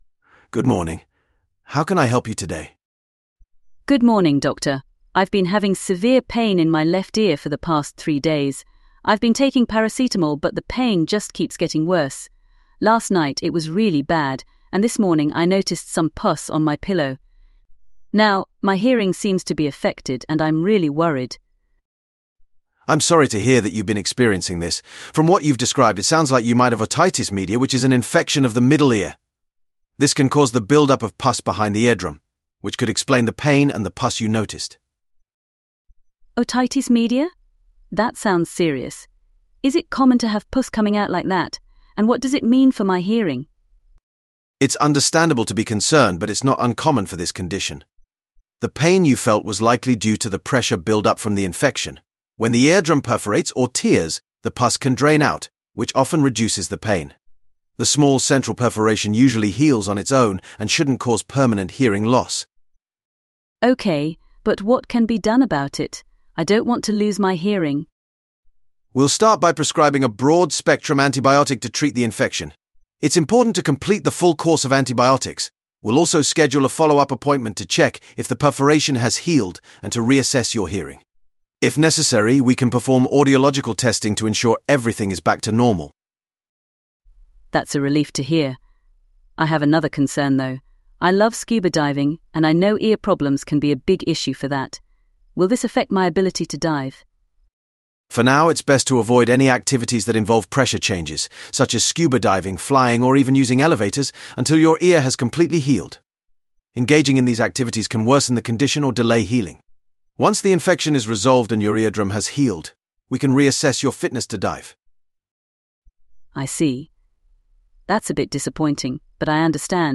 OET Speaking Sample Test One  – Role play card number for medicine: Reluctant patient.
Sample Answer
Overall, the conversation is empathetic, clear, and supportive, aligning well with the OET speaking criteria and demonstrating effective communication skills essential for healthcare professionals